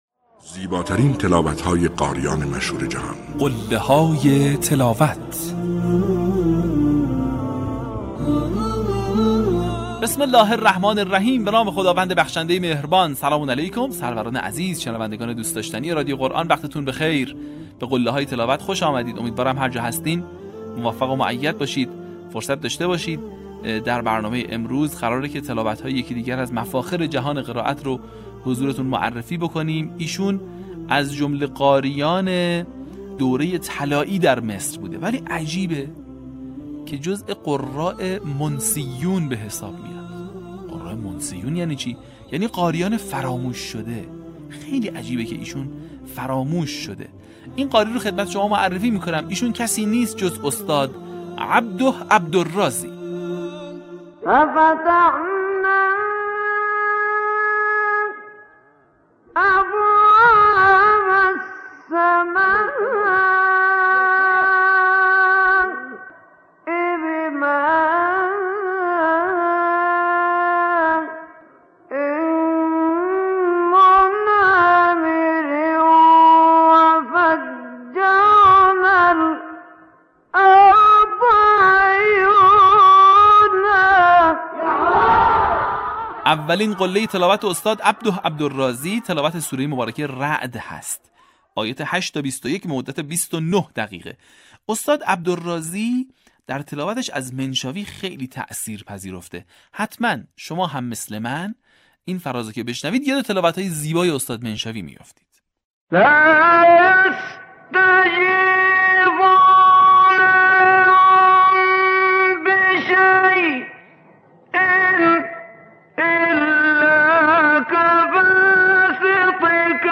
در قسمت پنجاه‌ونهم فراز‌های شنیدنی از تلاوت‌های به‌یاد ماندنی استاد «عبده عبدالراضی» را می‌شنوید.
برچسب ها: قله های تلاوت ، عبده عبدالراضی ، تلاوت قرآن